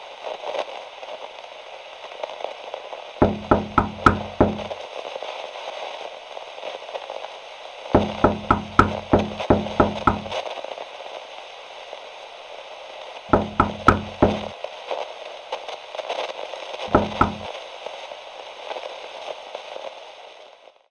recordknocks.mp3